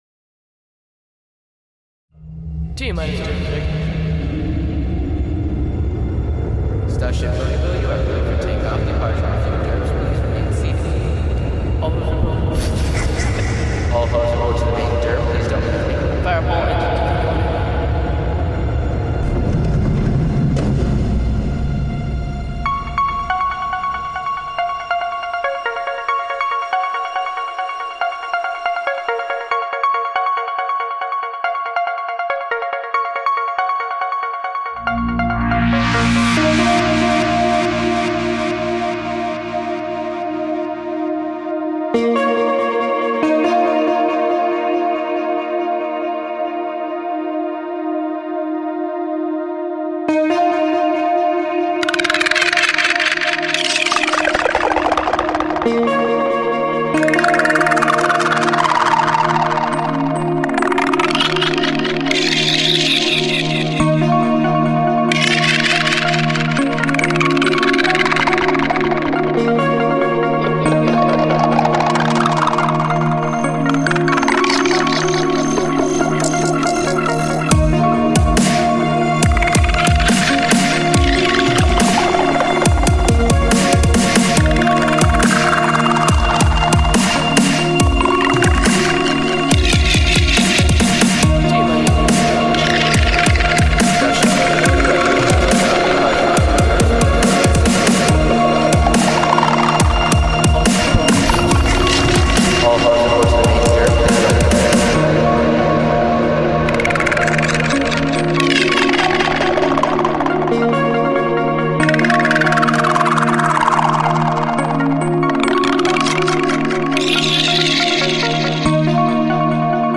Tempo 140bpm
Genre: Ambient / Trancestep / Dubstep